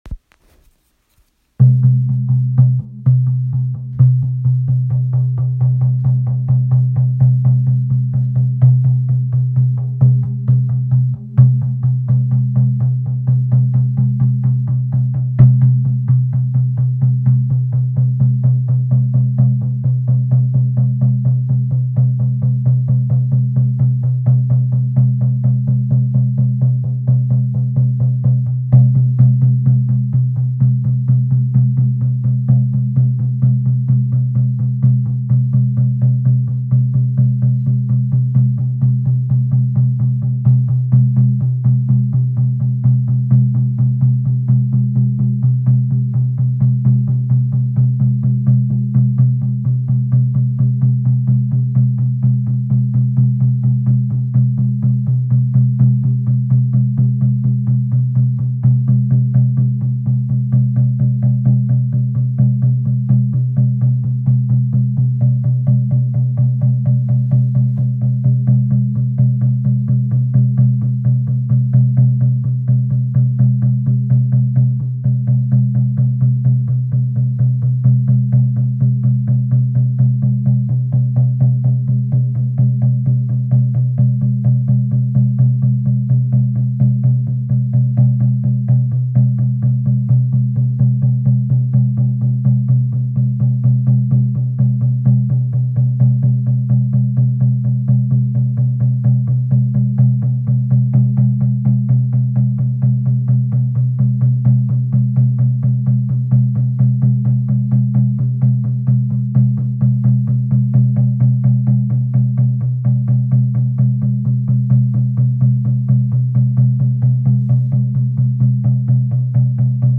Shamanic drumming with call back
This is a recording of drumming for shamanic journeying with call back. Original drumming with my moose healing drum.